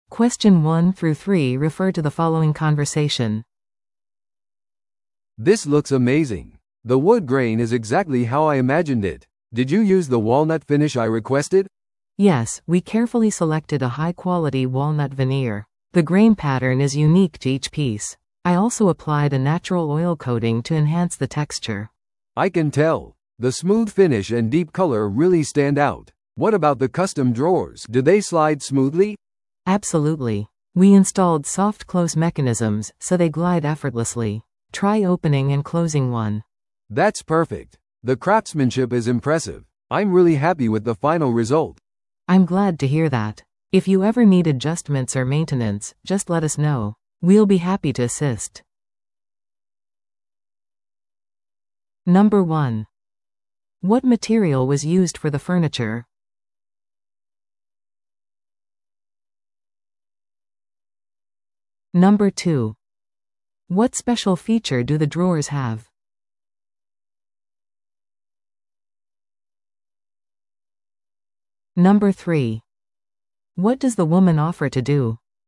TOEICⓇ対策 Part 3｜高級オーダー家具の検査と確認 – 音声付き No.77